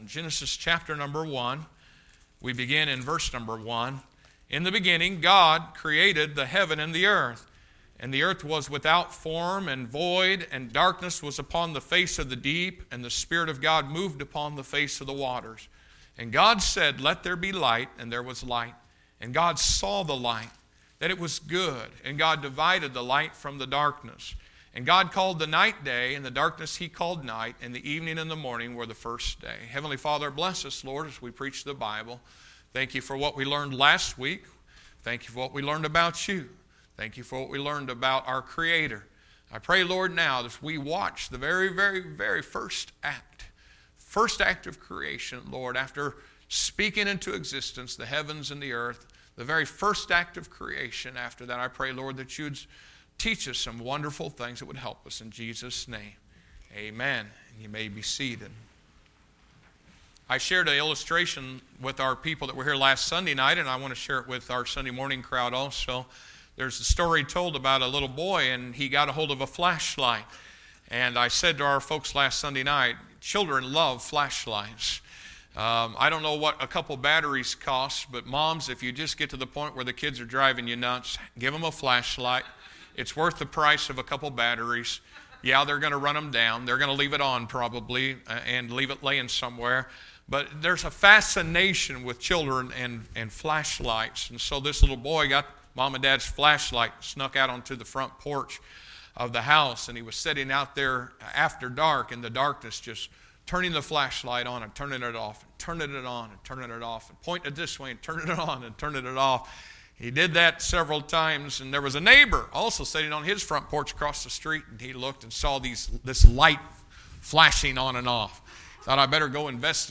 2014 SERMONS